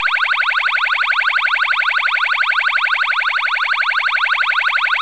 855T 传感器式声音模块
我们的声音模块最多可提供 15 种音调，并且音量、频率和速度均可调。
三角音调
tone65_triangle.wav